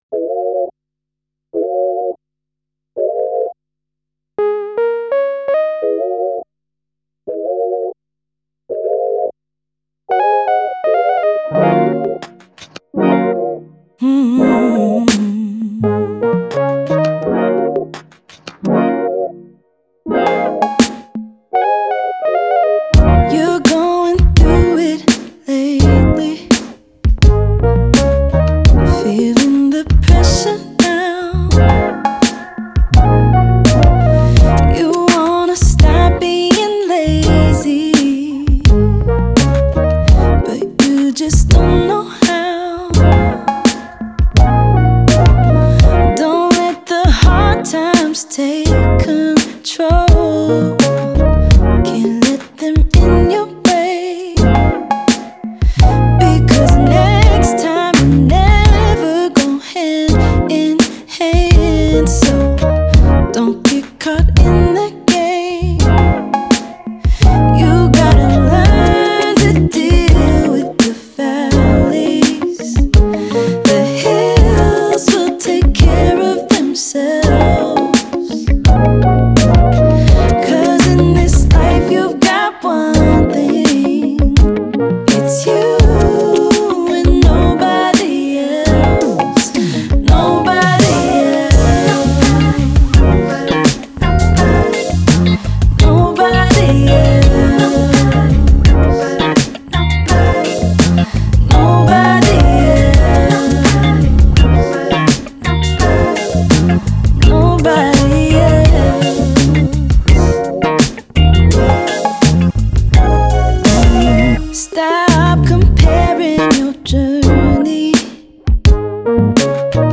это яркая и эмоциональная песня в жанре поп с элементами R&B